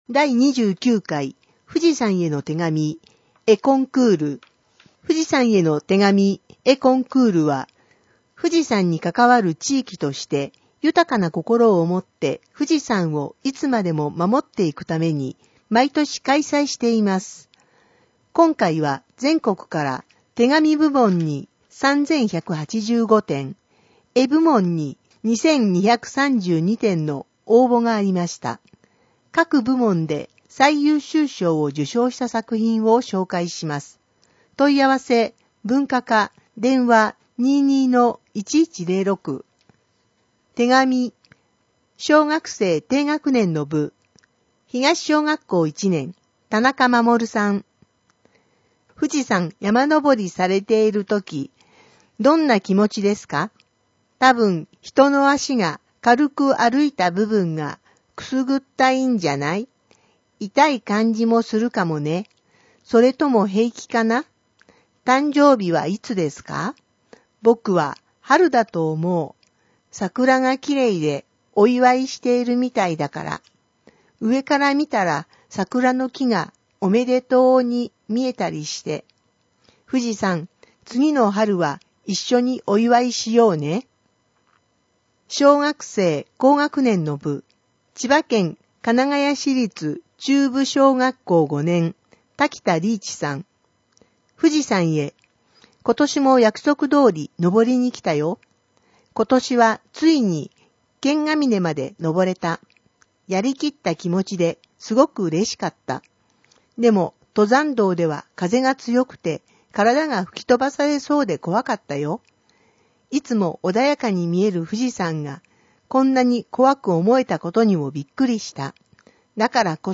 音訳ボランティア「やまびこ」の皆さんのご協力をいただき、「声の広報」を作成しています。